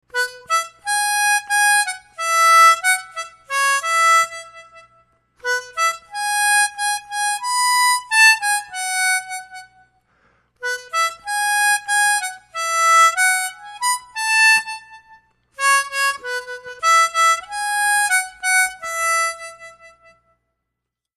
Red River Valley es una buena canción que podemos tocar en primera posición. La canción toca una octava sobre la que aparece en la partitura y se desarrolla en los orificios de 4 a 8.
Añade un poco de tremolo a las notas largas para que la canción suene más dulce.
Red river valley, a song for harmonica with tabs